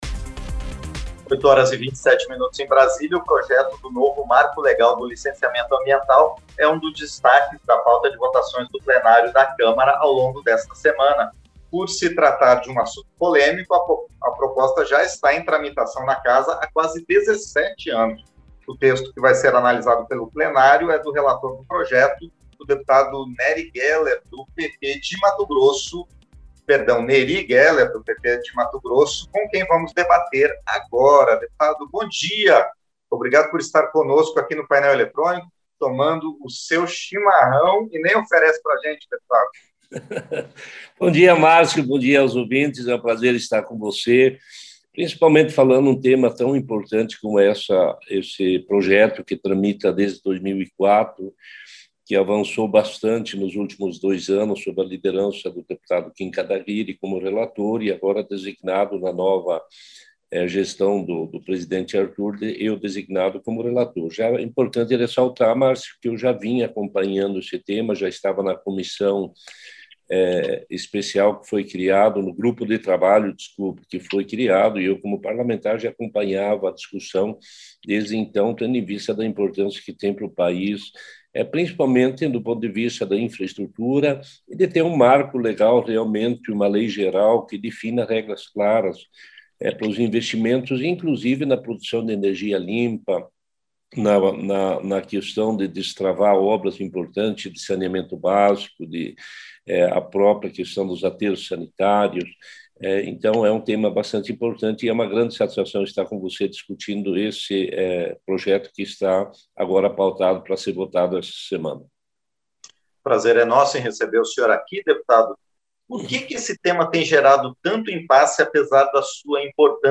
Entrevista - Dep. Neri Geller (PP-MT)